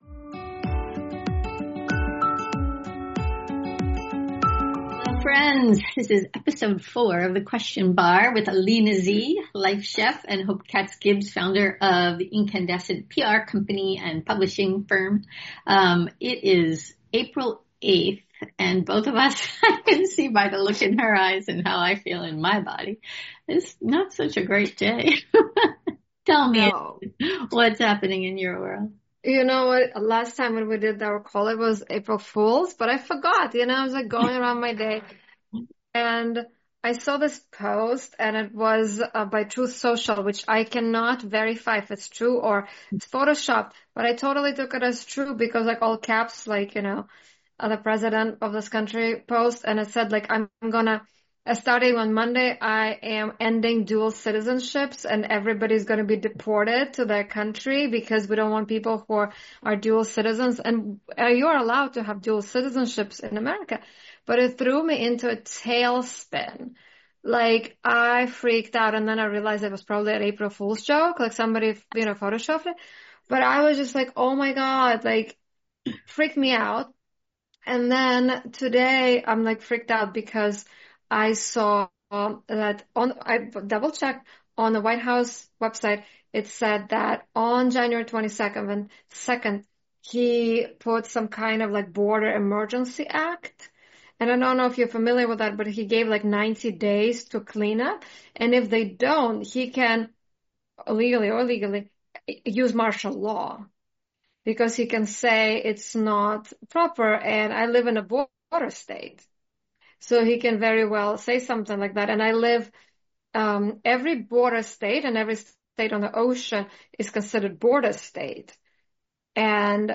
chat with two girlfriends talking about topics of the day